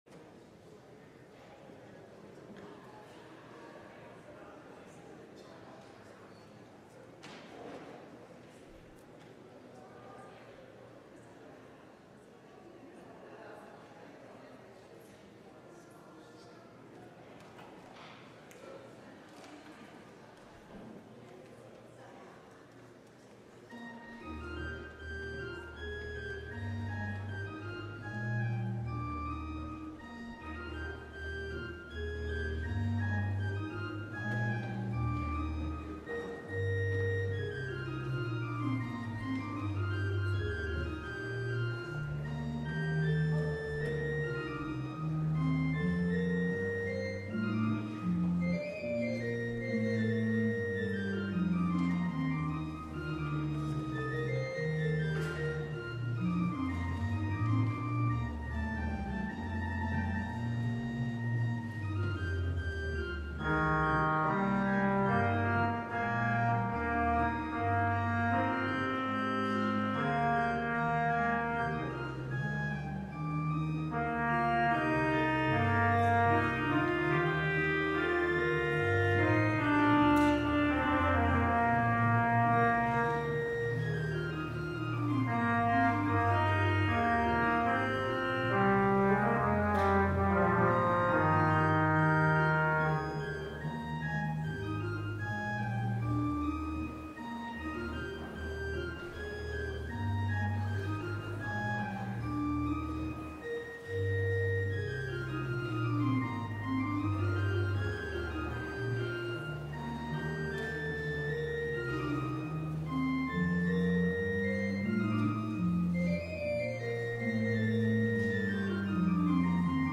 LIVE Midday Worship Service - The Women of the Genealogy: Rahab
It will also be a communion service!